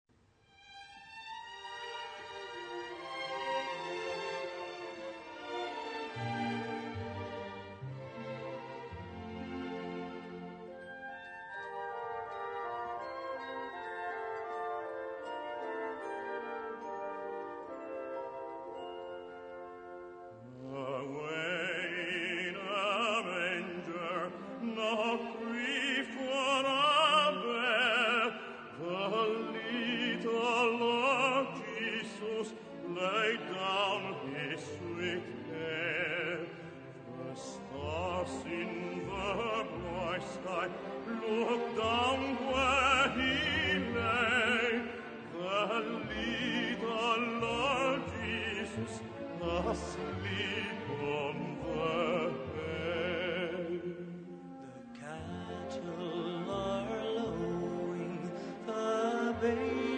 Piano & Vocal Score
key: F-major